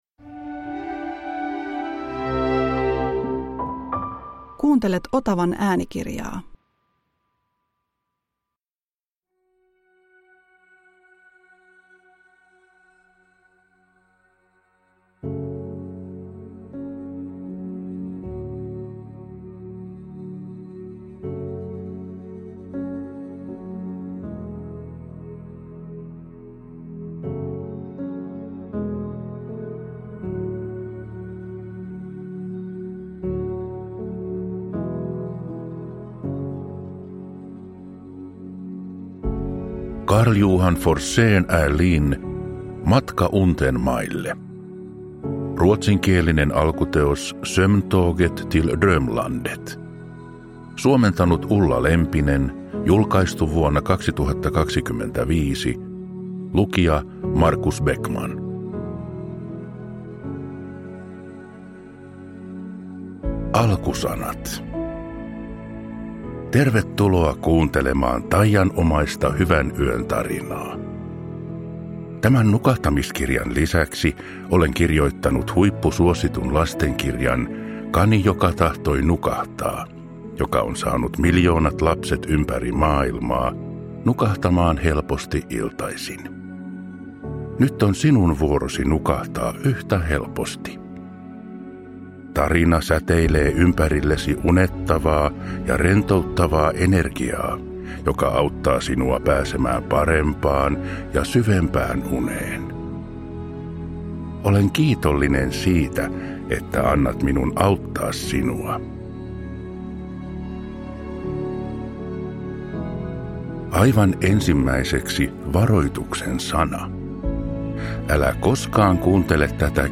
Äänikirjan musiikki ja äänimaailma on yhdistetty binauraalisiin lyönteihin syvän rentoutumisen aikaansaamiseksi ja unen laadun parantamiseksi.
• Ljudbok